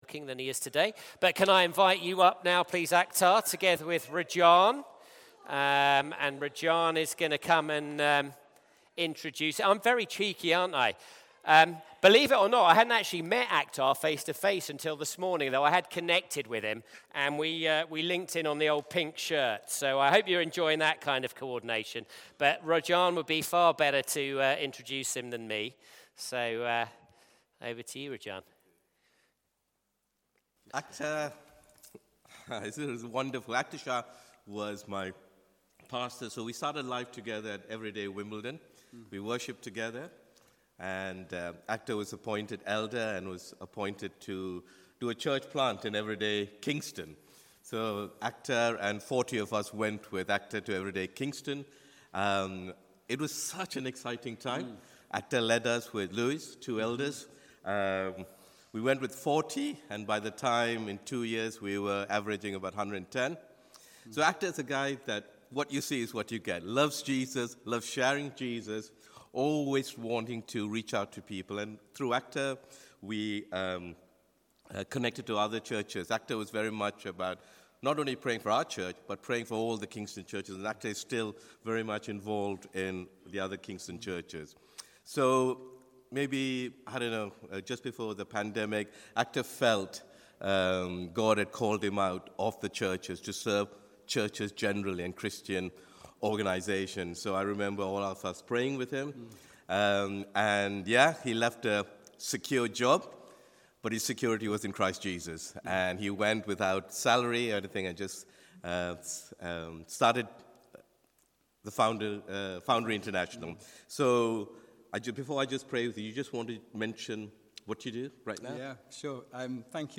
Sunday Service
Theme: Parable of the Great Banquet Sermon